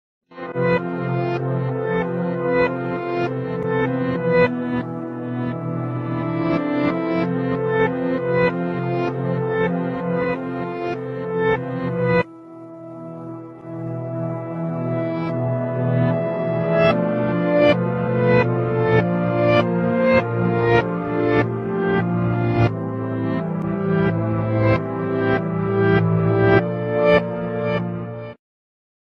GTA San Andreas mysterious sound sound effects free download